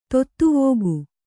♪ tottuvōgu